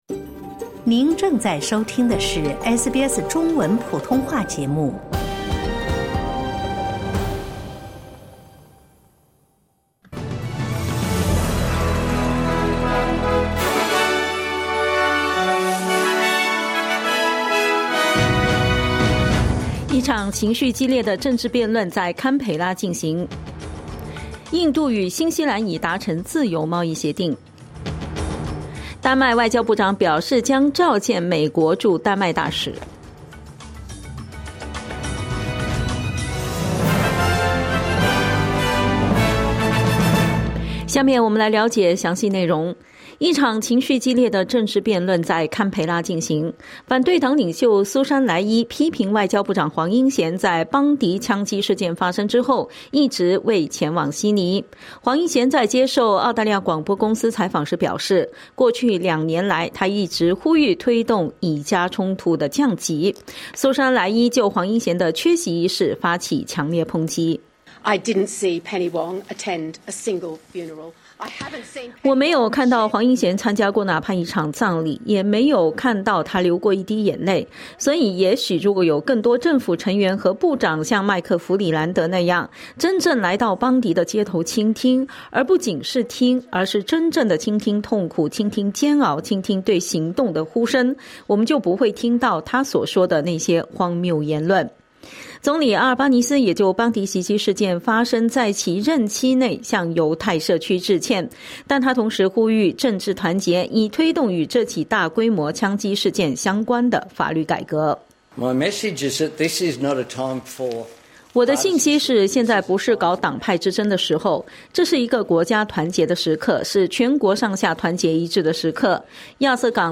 SBS早新闻（2025年12月23日）